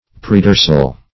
Search Result for " predorsal" : The Collaborative International Dictionary of English v.0.48: Predorsal \Pre*dor"sal\, a. (Anat.)